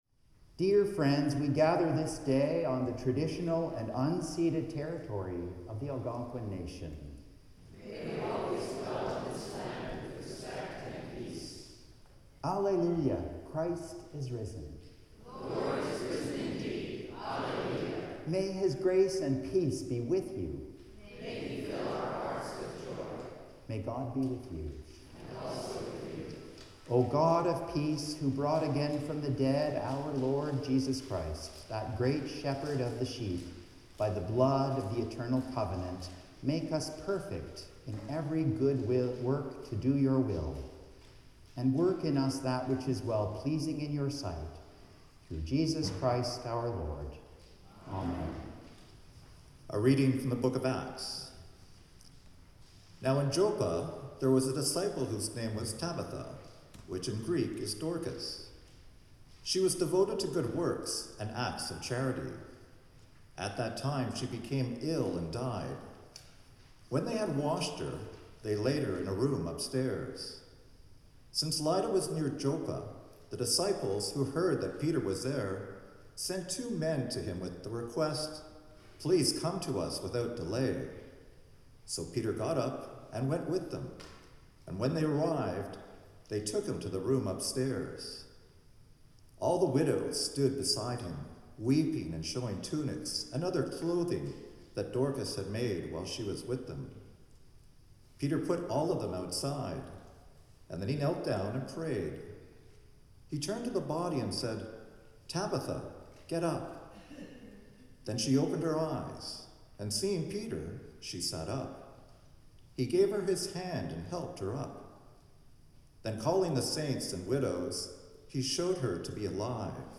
Music featuring the Strings of St John's
(Reading in French) Hymn 519: The Lord's My Shepherd
The Lord’s Prayer (sung)